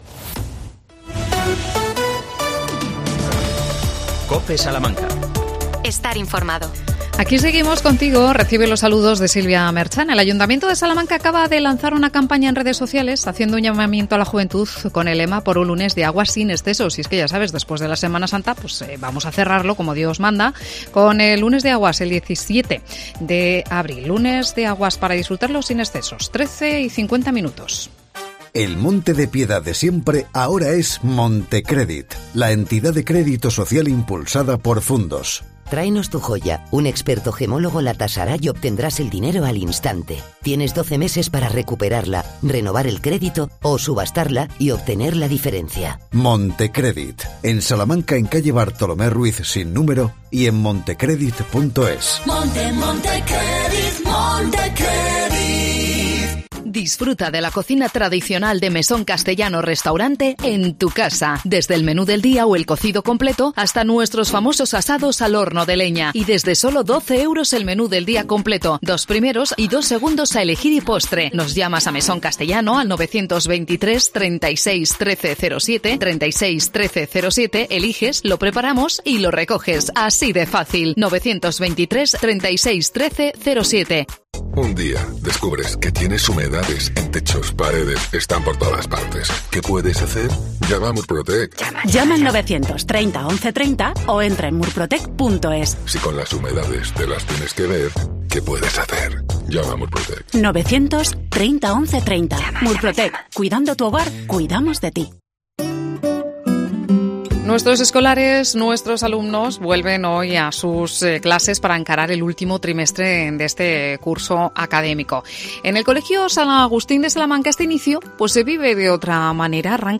AUDIO: Arranca la VII Semana de Formación del Colegio San Agustín. Entrevista